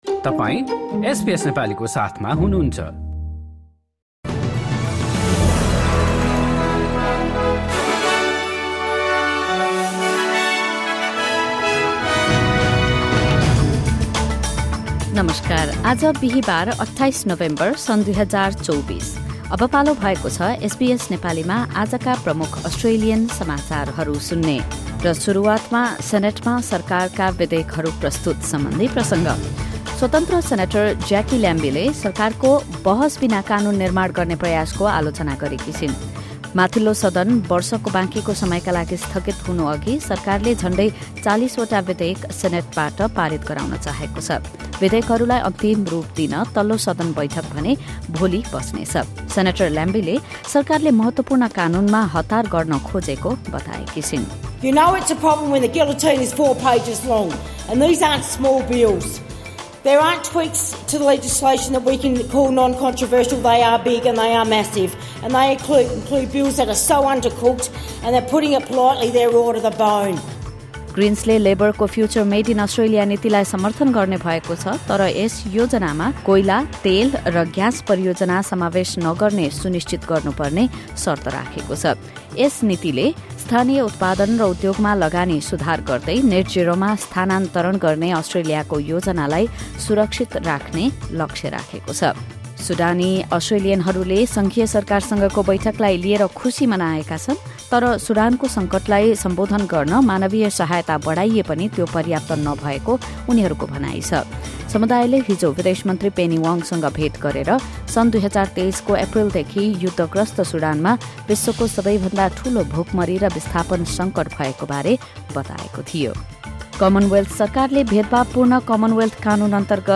SBS Nepali Australian News Headlines: Thursday, 28 November 2024